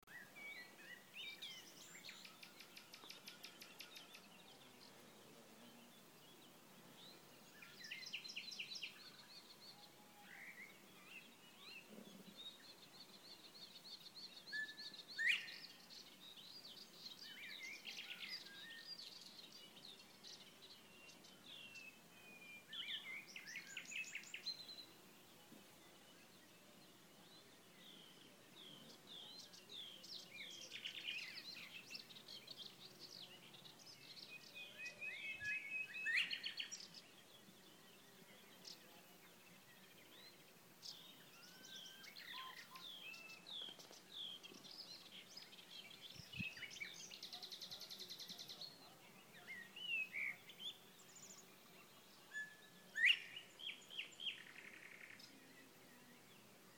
chant d'oiseau mystérieux en Istrie, à Vizinada
J'ai réalisé cet enregistrement en Istrie, Croatie à Vizinada, je n'ai jamais entendu ce chant unique et fort, au milieu du merle et autre rossignol.
non cet oiseau chantait d'un sous bois en bas... je ne pense pas à un psittacidae... j'ai observé a quelques kilomètres un Étourneau roselin, Sturnus roseus mais je ne trouve pas son chant, et ce n'est pas un chant d'étourneau, trop puissant...
chantsvizinada.mp3